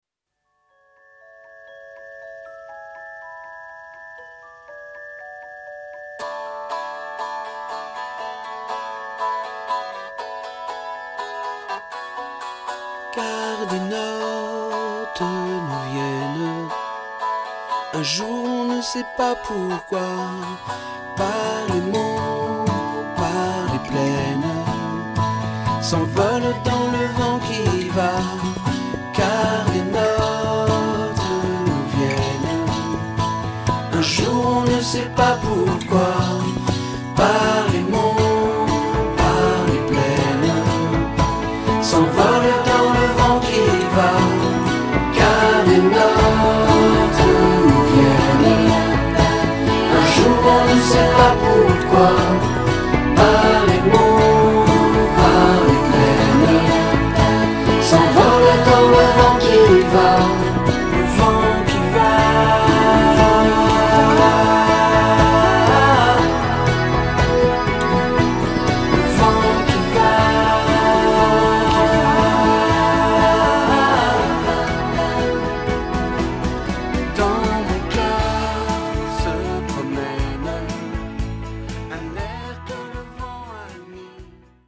tonalité LA majeur